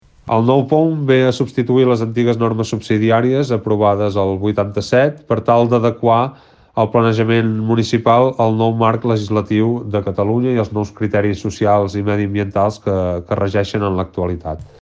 Ho ha explicat l’alcalde del municipi, Ignasi Sabater, a Ràdio Capital.